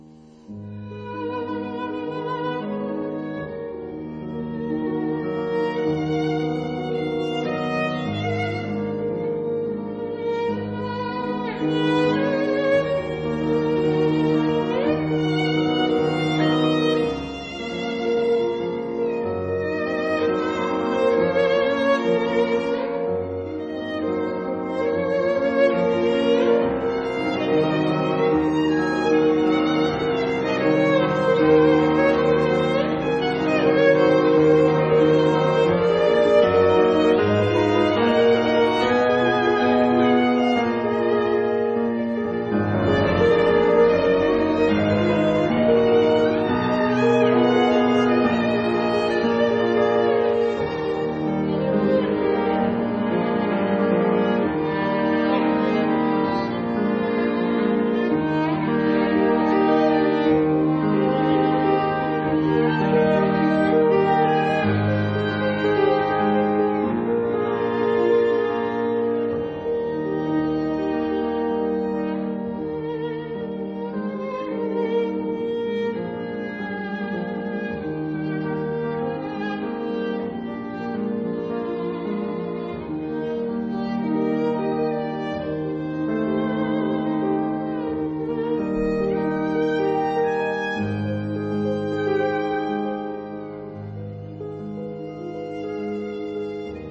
法朗克的弦樂四重奏則是更加收斂情感，
但卻有著歌曲般的優美，與動態對比。
晚安曲（試聽一、二）選了兩段弦樂四重奏，分別是第一、二樂章。